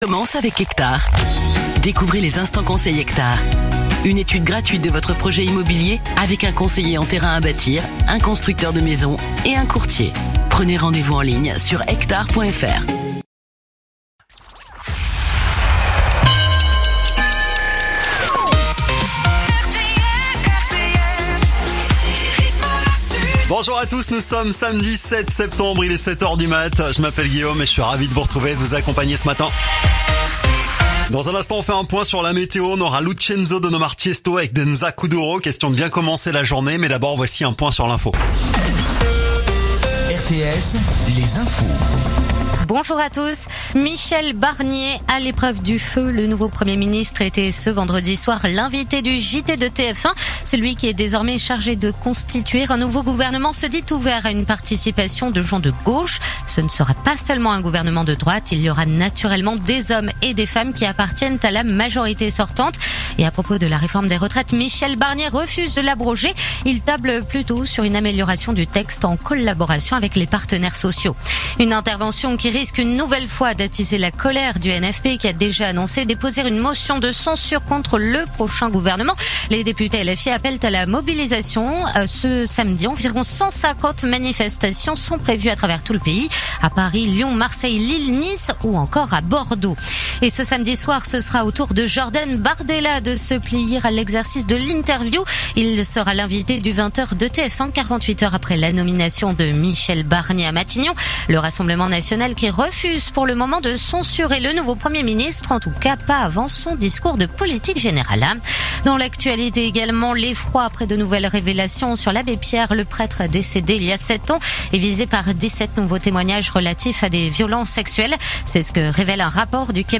info_nimes_134.mp3